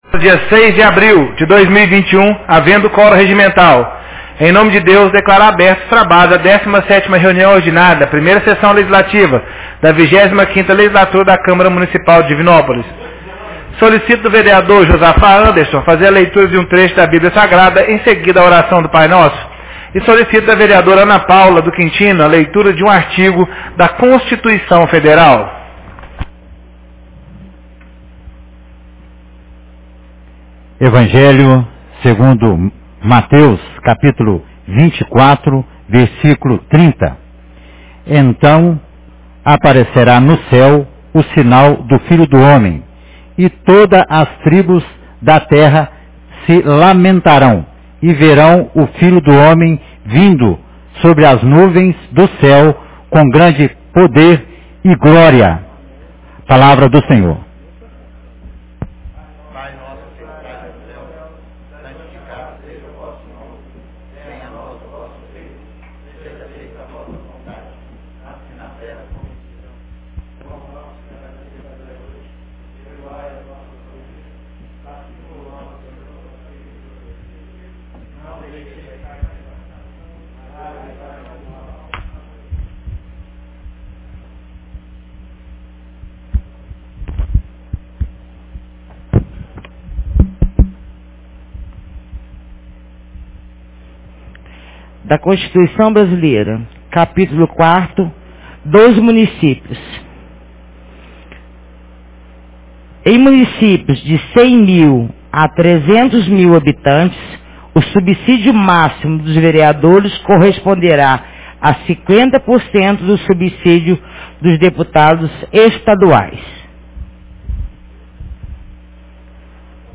Reunião Ordinária 17 de 06 de abril 2021